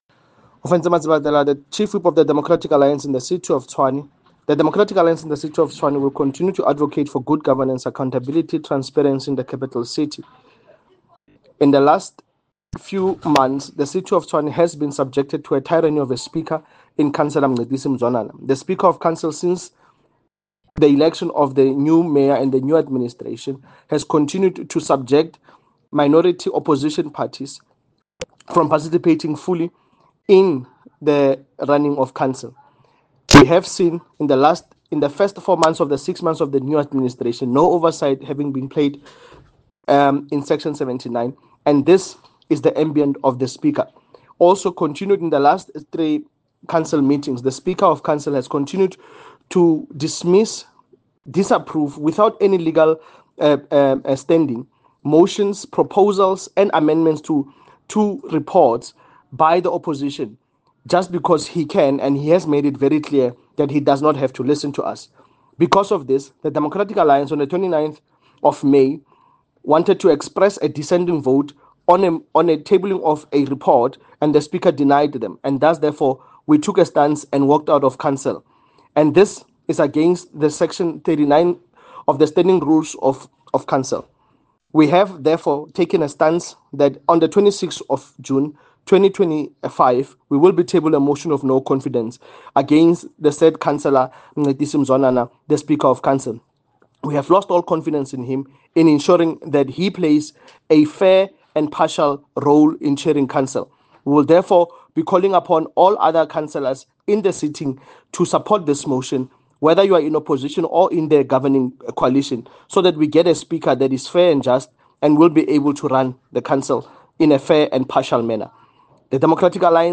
Note to Editors: Please find an English soundbite by Cllr Ofentse Madzebatela